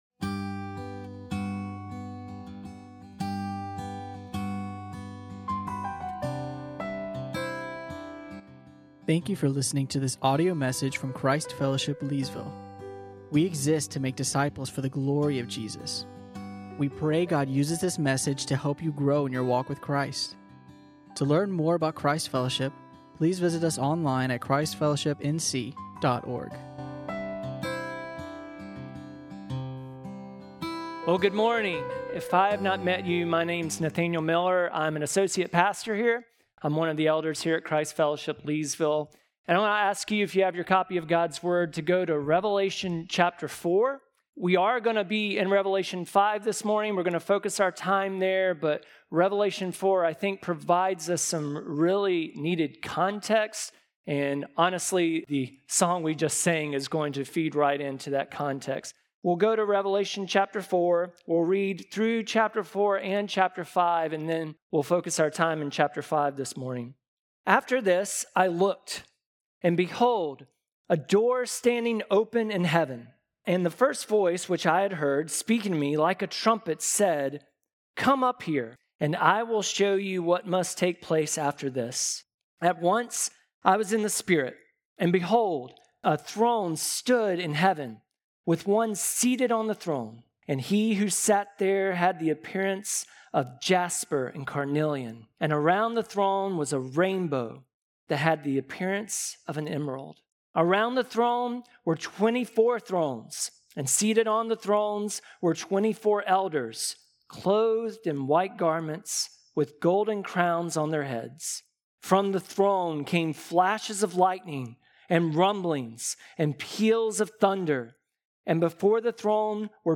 teaches on Revelation 5.